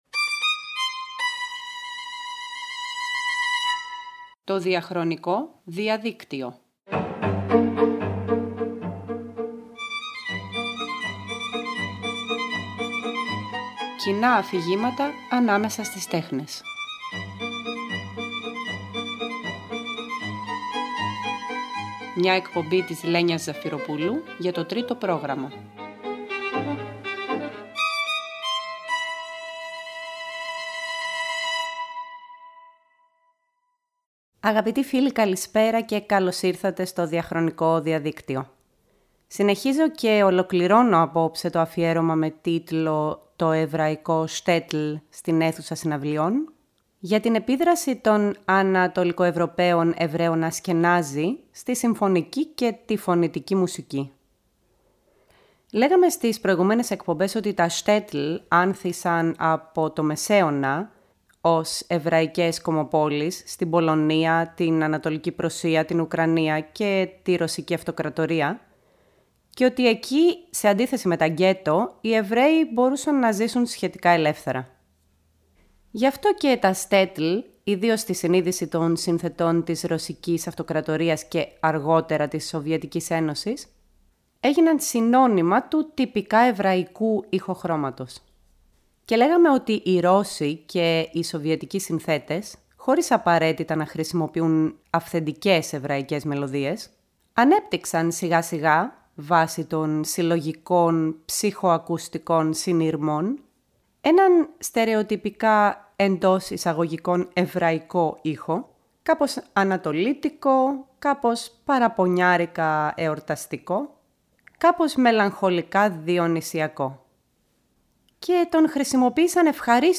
Στο αφιέρωμα αυτό θα ακούσουμε μαζί τέτοια έργα, όπως και μουσική ζώντων συνθετών που εμπνέονται από τη μουσική παράδοση της εβραϊκής διασποράς.